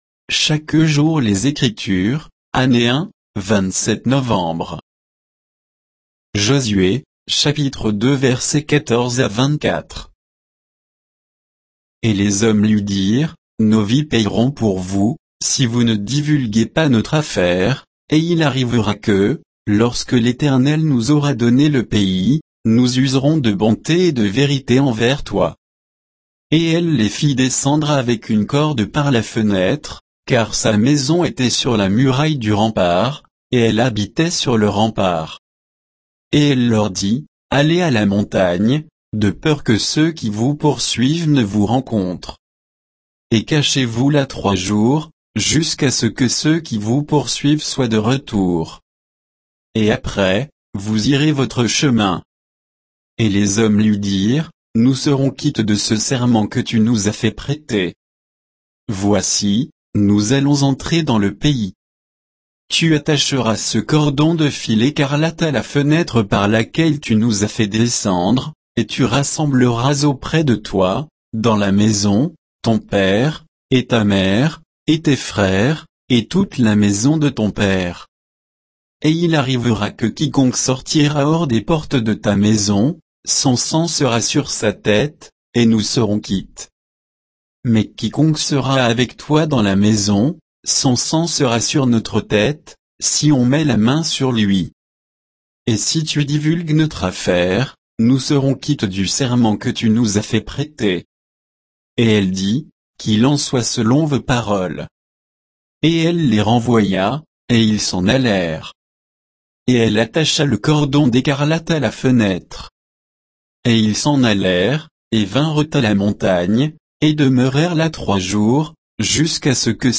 Méditation quoditienne de Chaque jour les Écritures sur Josué 2